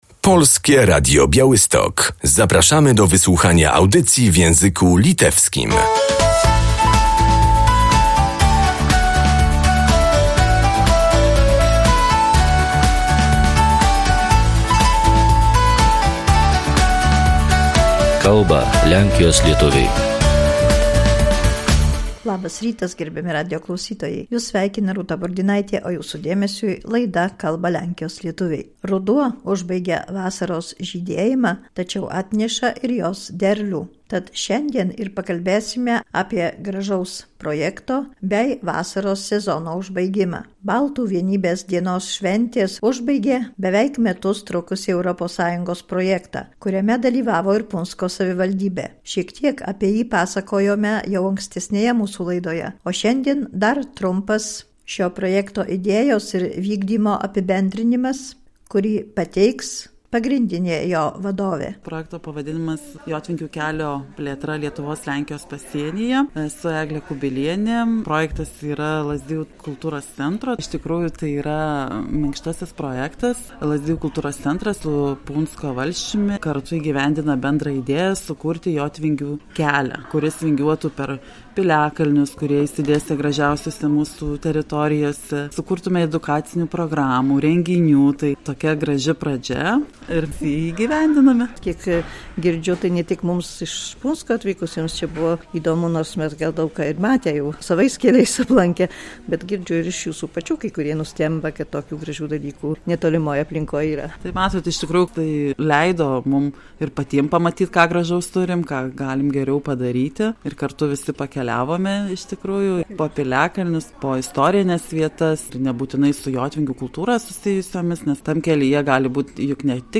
Fiestą ognia w Rudamina i w Oszkiniach zakończył się projekt, dotyczący rozszerzenia szlaku Jaćwingów na pograniczu Polski i Litwy. O celach oraz przebiegu realizacji projektu mówią jego autorzy i uczestnicy z Lazdijai i z Puńska.